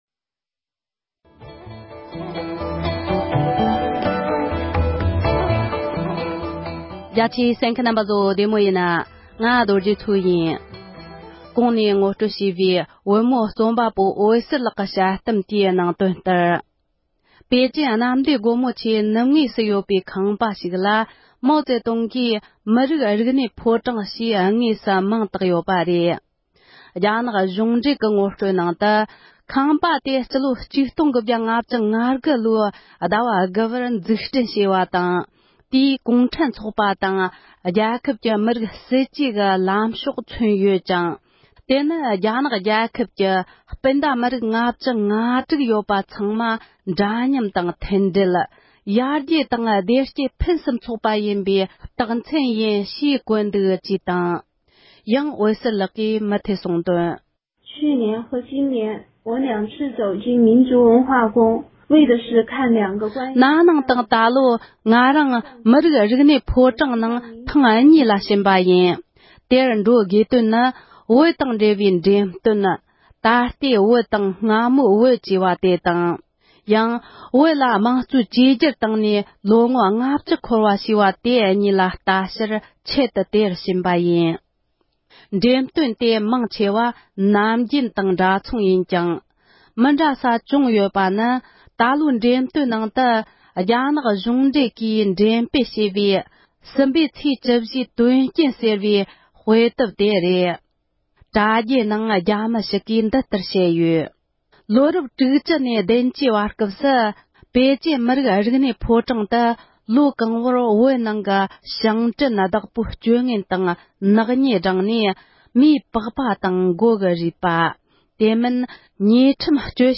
བོད་སྐད་དུ་ཕབ་བསྒྱུར་དང་སྙན་སྒྲོན་ཞུས་པ་ཞིག་གསན་རོགས་གནང་༎